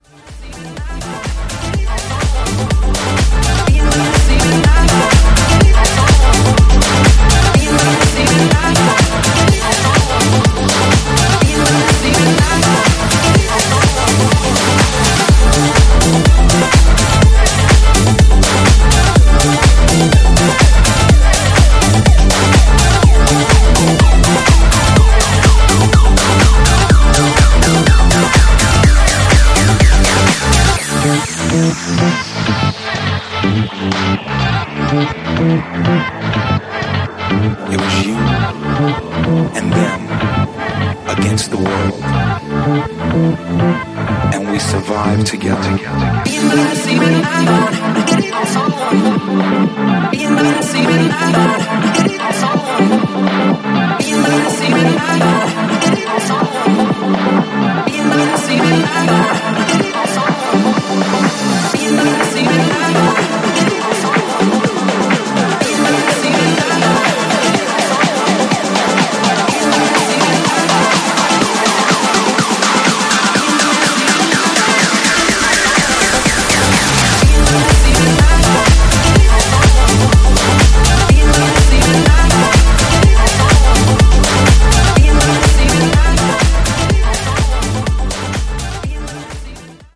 ジャンル(スタイル) DISCO HOUSE